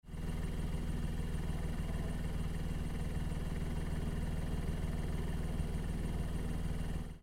それでは排気音を収録してきたのでどうぞ・・
純正マフラー（アイドリング）
copen_la400-normal_idling.mp3